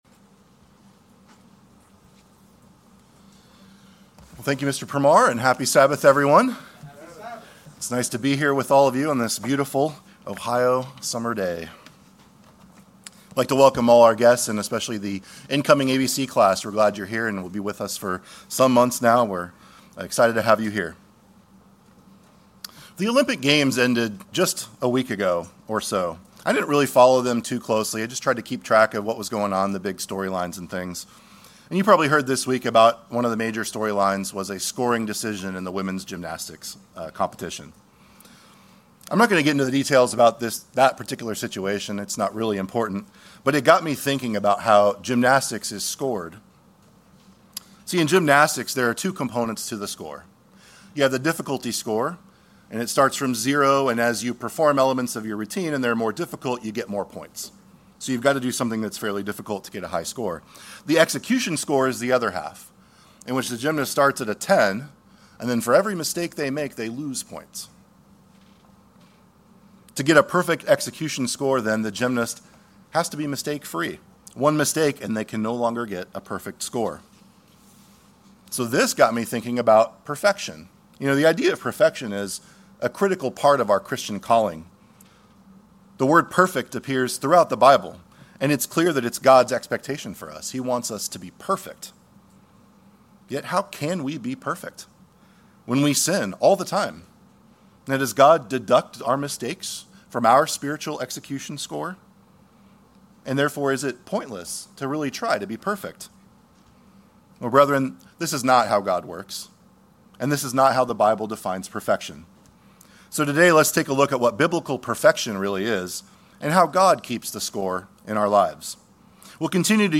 Given in Cincinnati East, OH